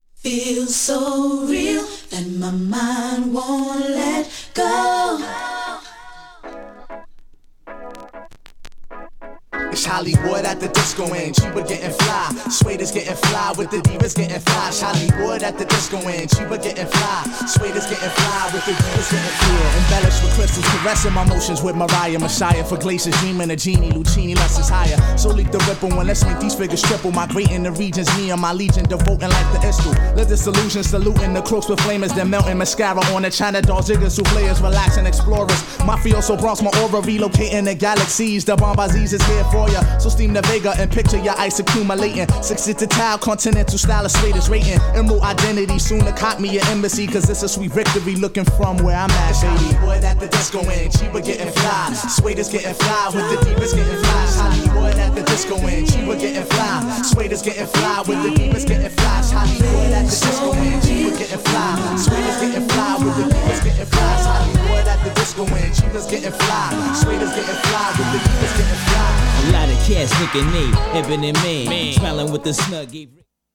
GENRE Hip Hop
BPM 96〜100BPM
SMOOTHなフロウ
# 渋い系HOPHOP